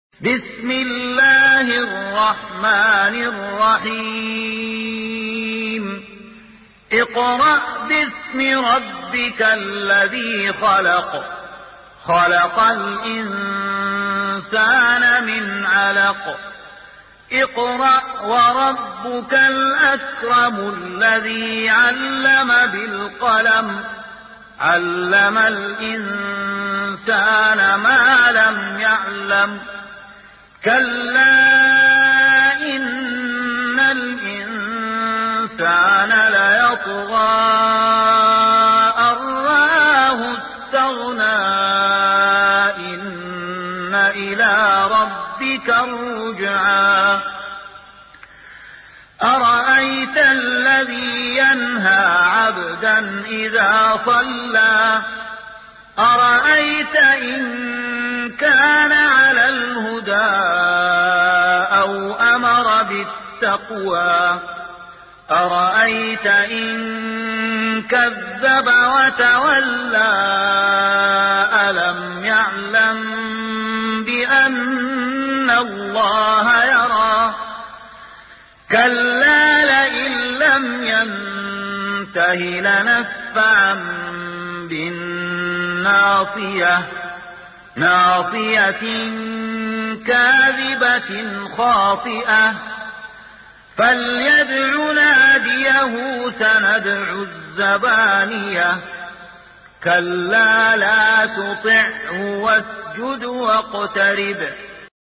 سورة العلق | القارئ